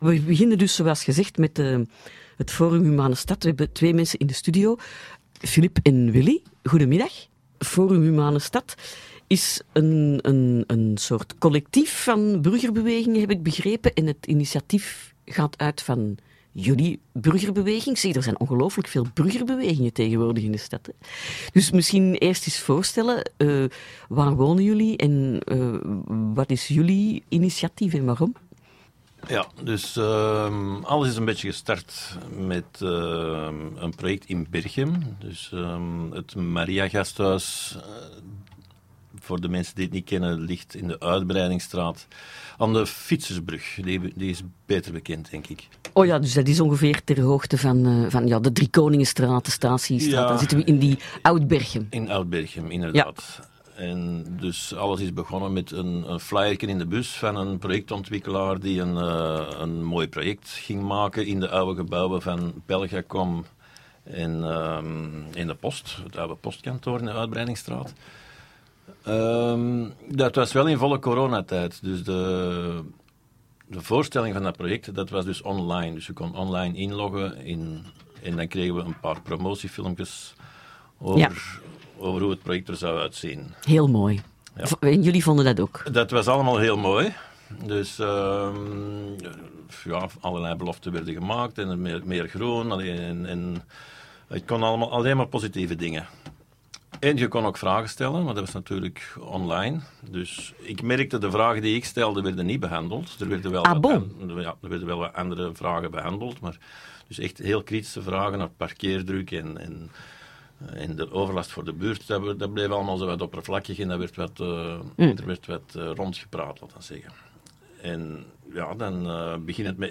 van Geen 8 hoog in de studio.